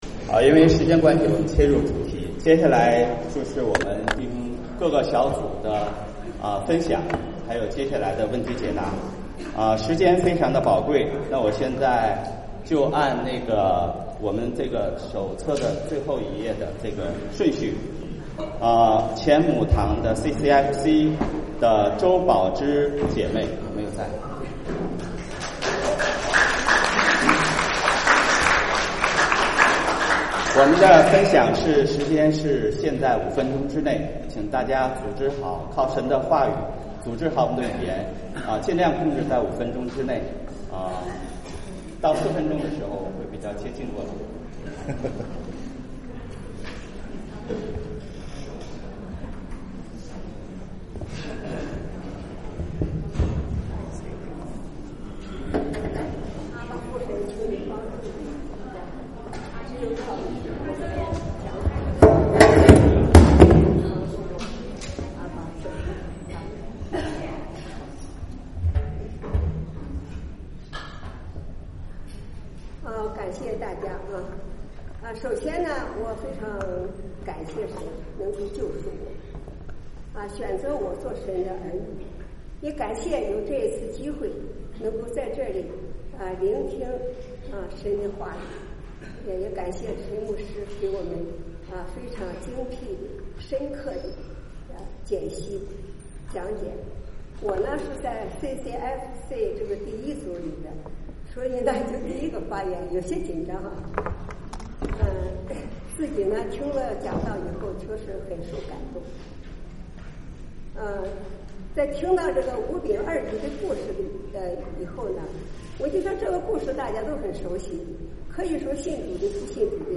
[2025夏令退修會] 見證分享